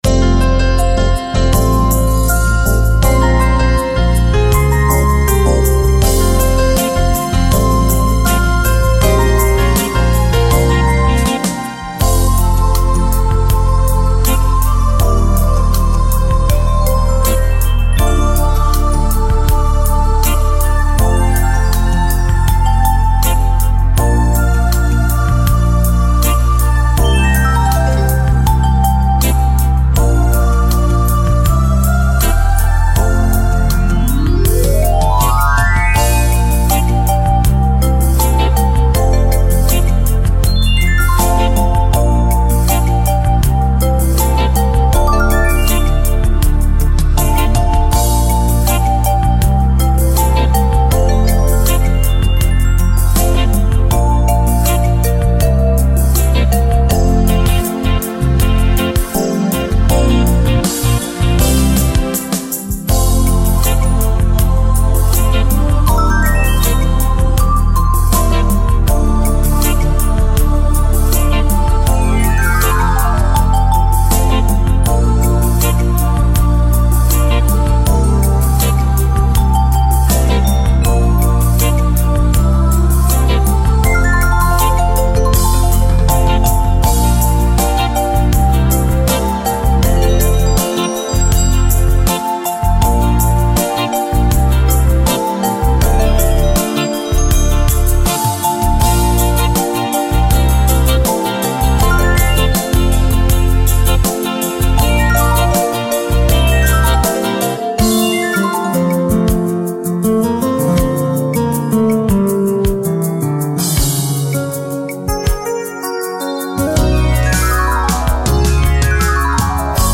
фоновая музыка, видеоролик физминутки, заготовка для рисования, мультфильм.